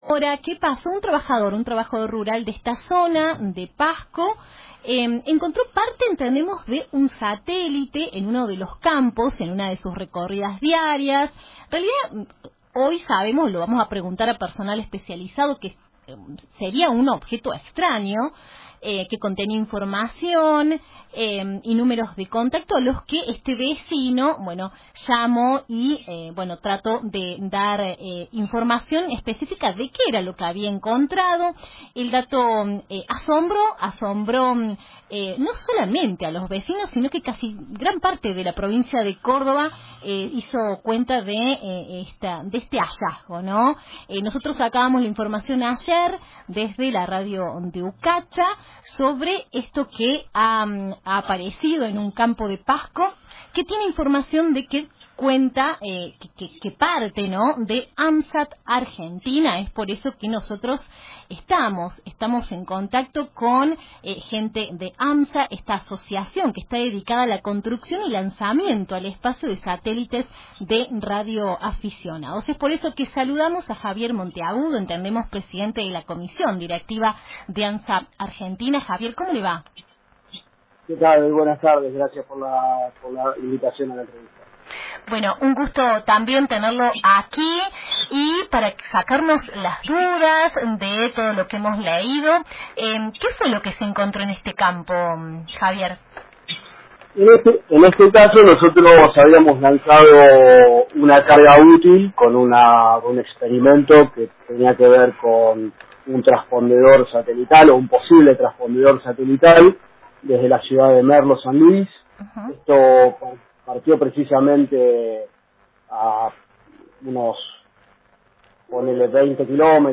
Entrevista Recuperación